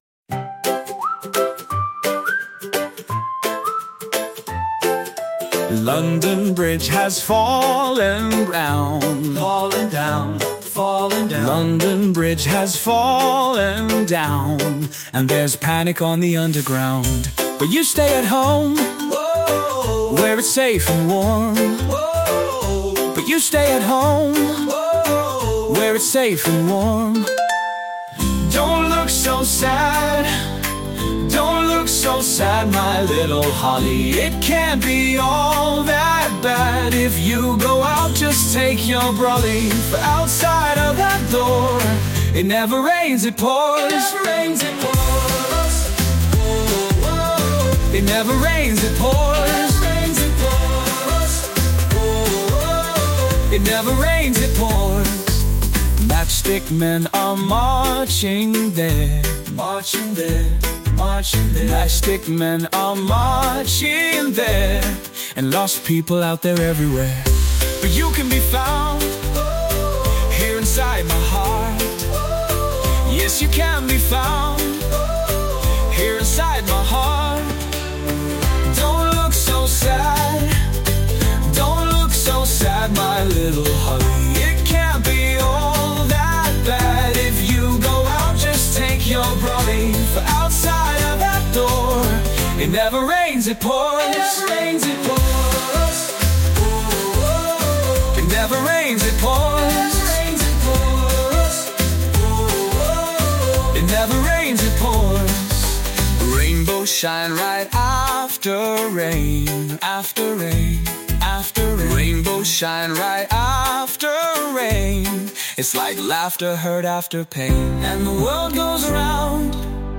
This children’s song